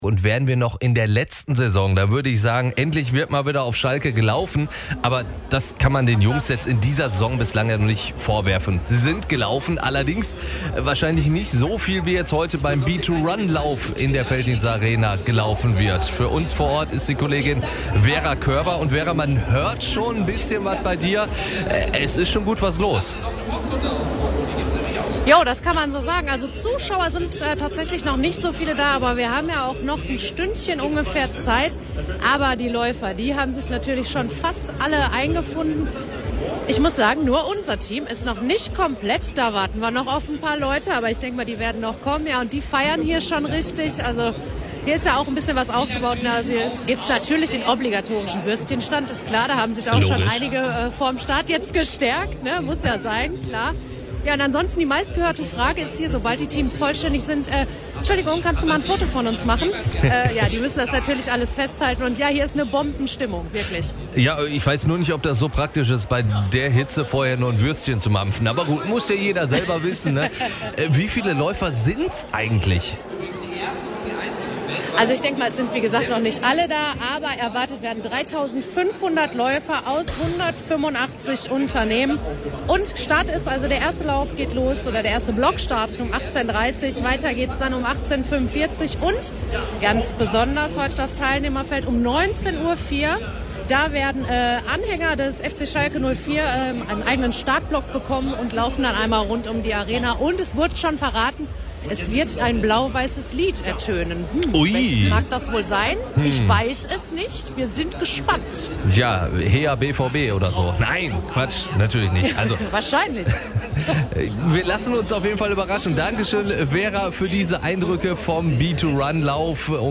Gestern waren über 3.500 Teilnehmer aus rund 185 Unternehmen auf Schalke beim B2Run. Mittendrin unsere Reporterin.
b2run-live.mp3